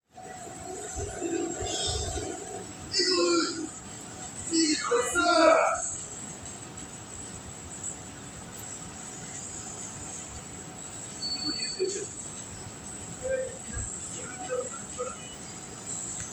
宮下パーク前1.wav